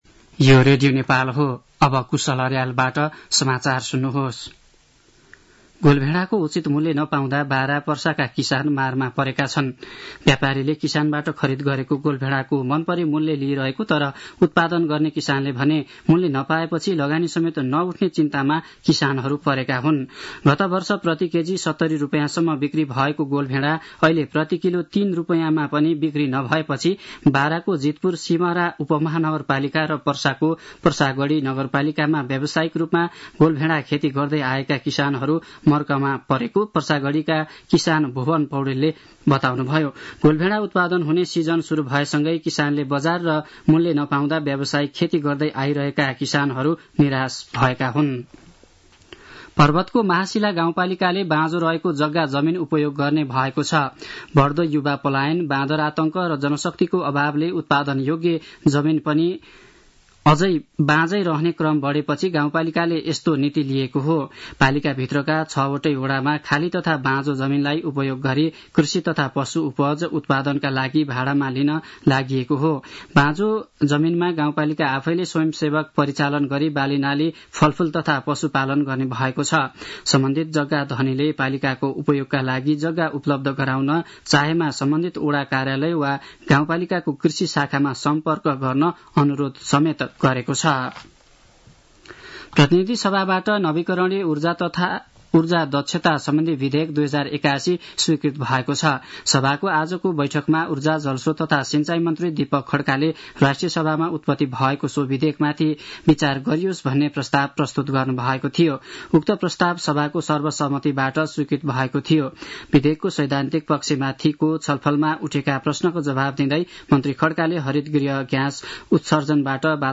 साँझ ५ बजेको नेपाली समाचार : २४ फागुन , २०८१